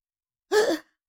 Cartoon Little Child, Voice, Sigh, Surprise 3 Sound Effect Download | Gfx Sounds
Cartoon-little-child-voice-sigh-surprise-3.mp3